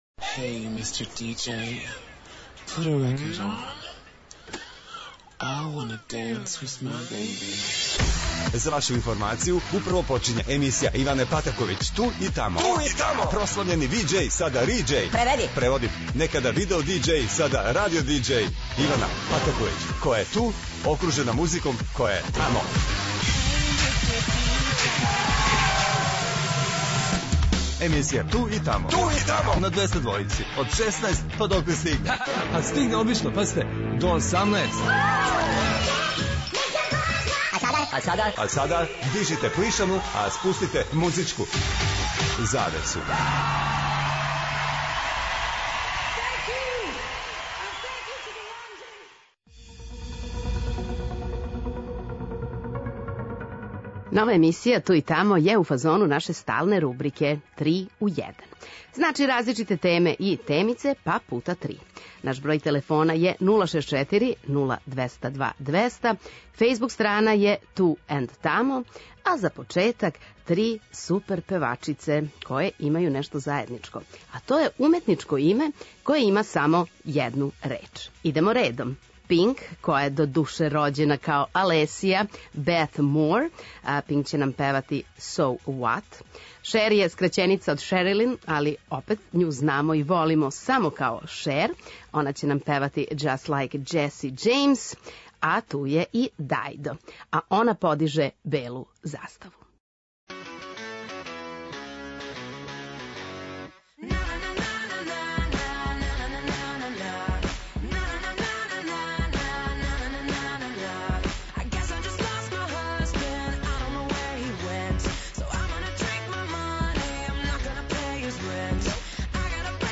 То обећава супер музику и провод на Двестадвојци...
Очекују вас велики хитови, страни и домаћи, стари и нови, супер сарадње, песме из филмова, дуети и још много тога.